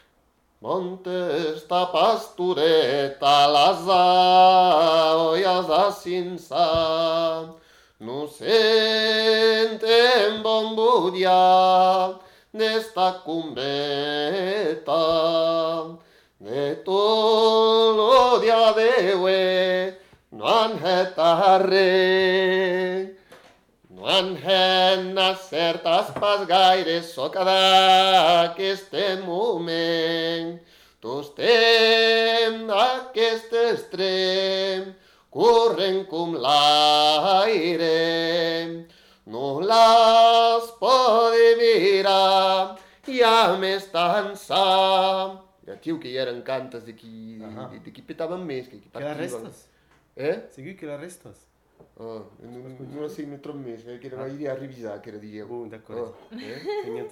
Aire culturelle : Bigorre
Lieu : Ayzac-Ost
Genre : chant
Effectif : 1
Type de voix : voix d'homme
Production du son : chanté